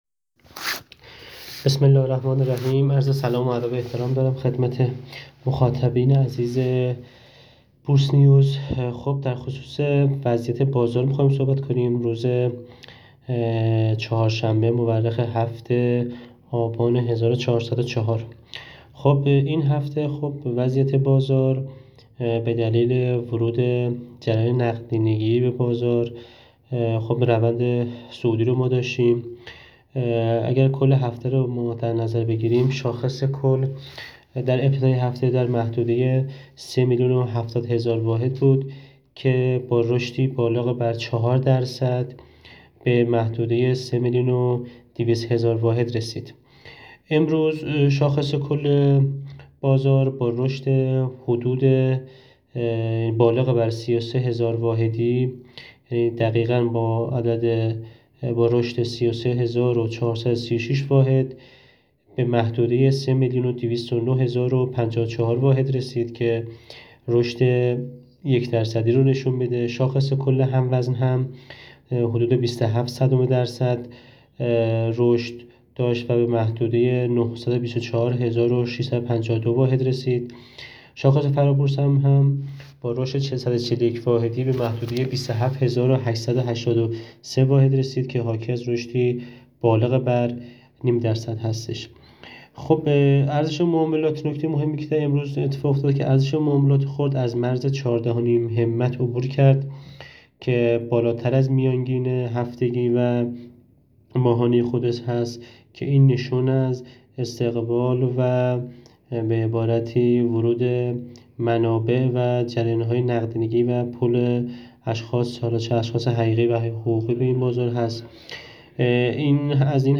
در گفت‌و‌گو با بورس‌نیوز